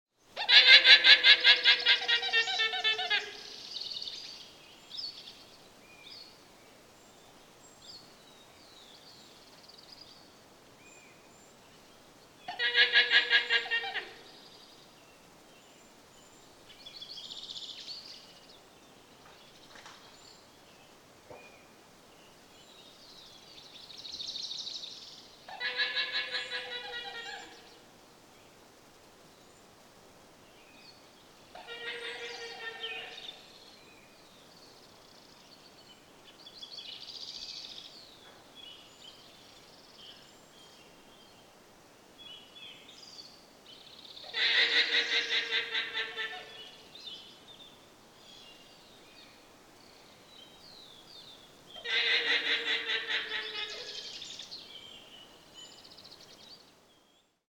Голос гуанако среди деревьев